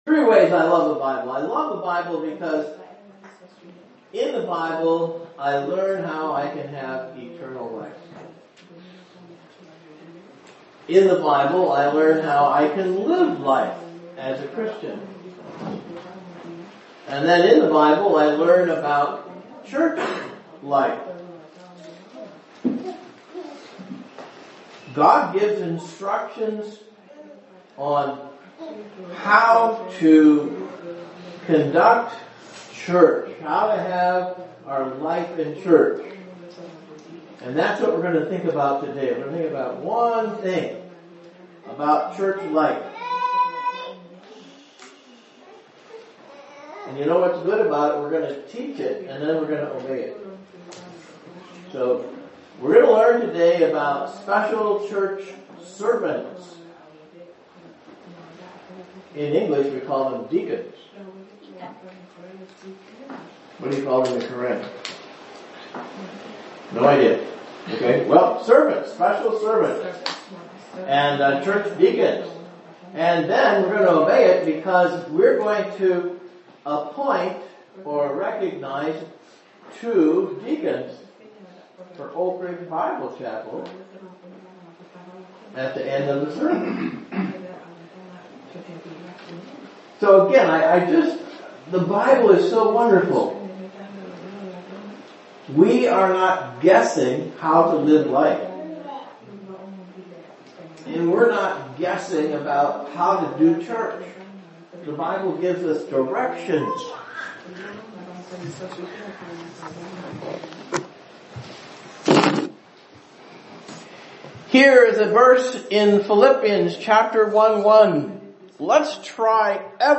Bible Messages – Oak Ridge Bible Chapel, Milan, Illinois
What does the Bible teach about deacons. Also appointment of deacons at ORBC on 6/1/2025